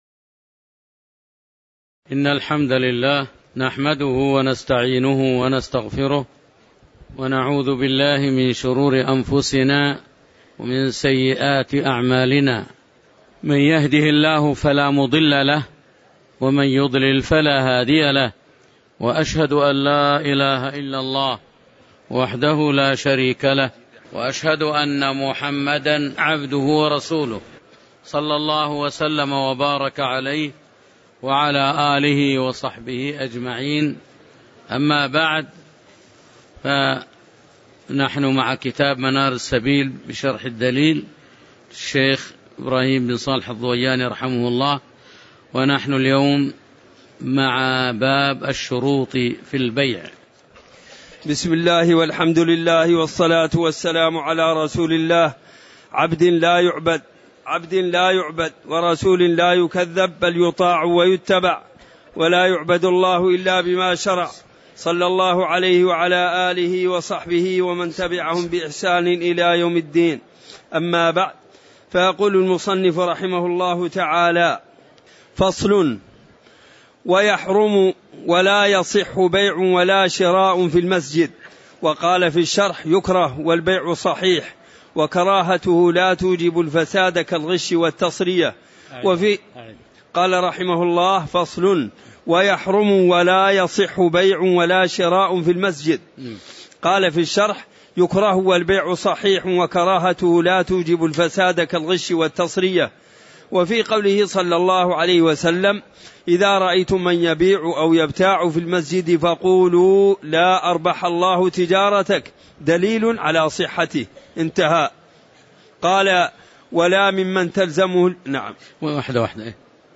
تاريخ النشر ٥ محرم ١٤٤٠ هـ المكان: المسجد النبوي الشيخ